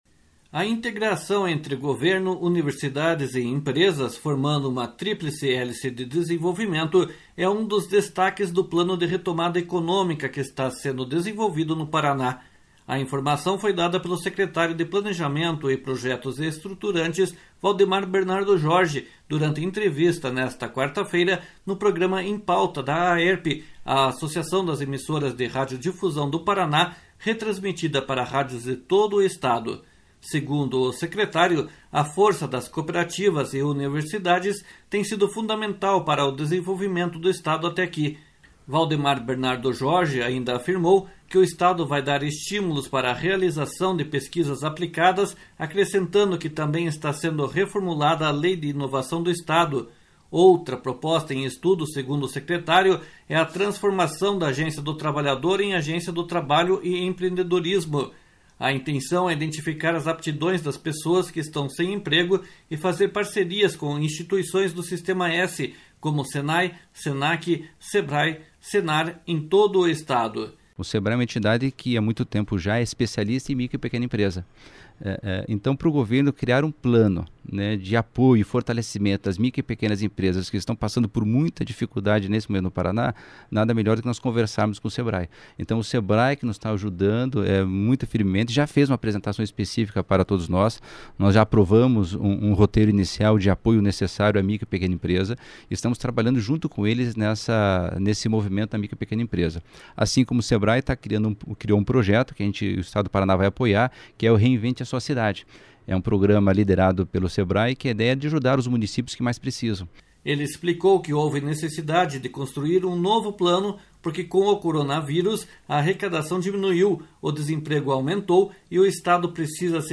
A informação foi dada pelo secretário de Planejamento e Projetos Estruturantes, Valdemar Bernardo Jorge, durante entrevista nesta quarta-feira no programa Em Pauta, da AERP, a Associação das Emissoras de Radiodifusão do Paraná, retransmitida para rádios de todo o Estado.
// SONORA VALDEMAR BERNARDO JORGE //